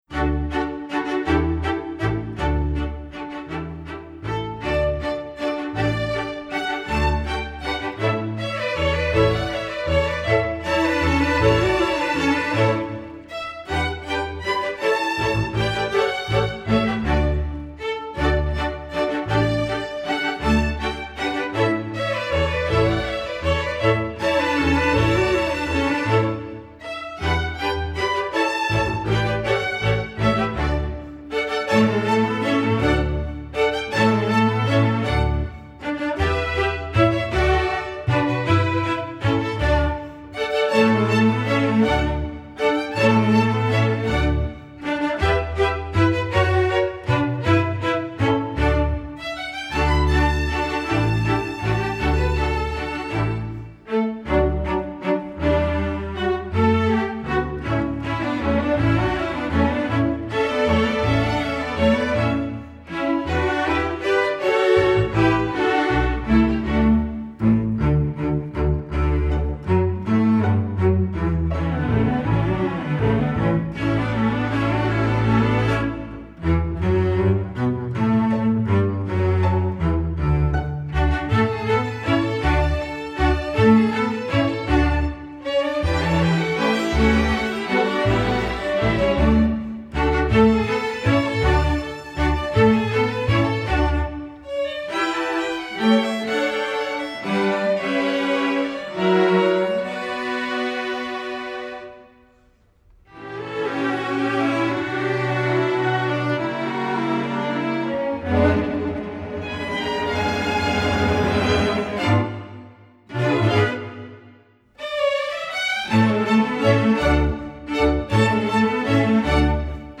folk, latin